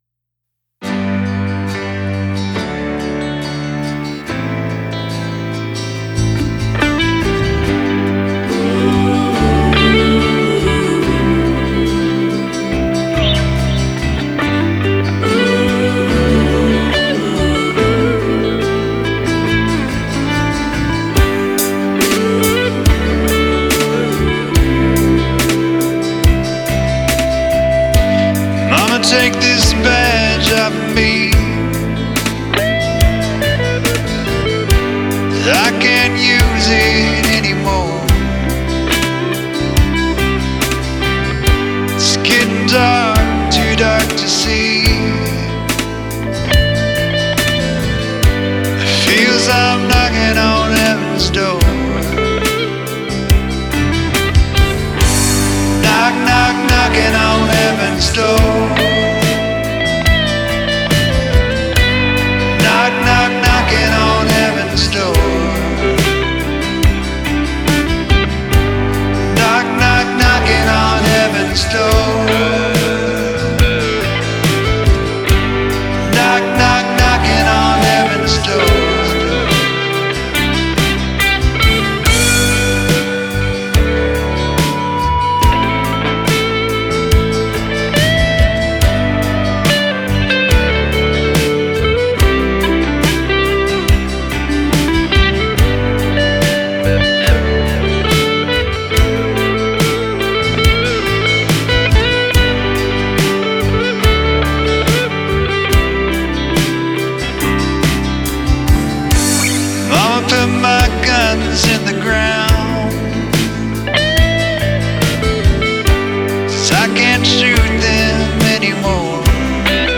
in a hotel room in Modesto, CA while on a business trip